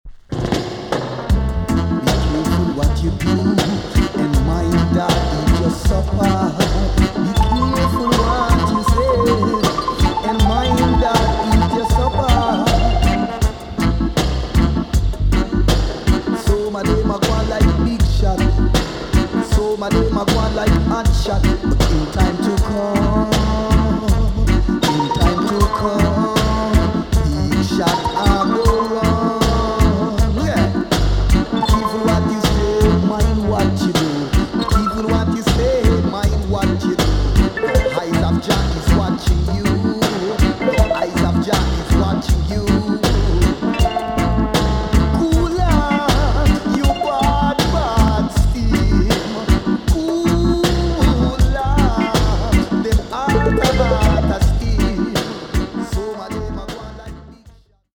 TOP >REGGAE & ROOTS
EX- 音はキレイです。
1979 , RARE , WICKED ROOTS VOCAL TUNE!!